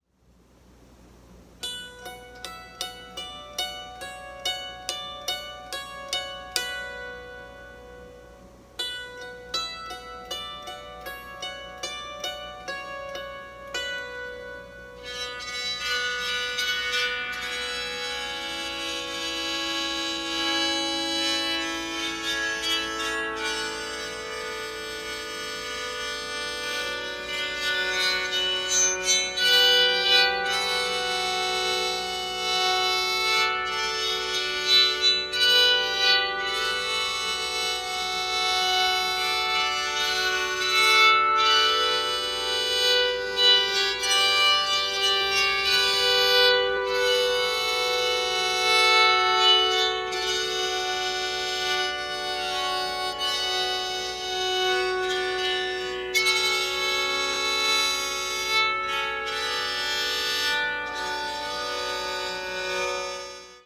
Traditional Romanian Instrument
Arch Psaltery
Sound-of-the-instrument.wav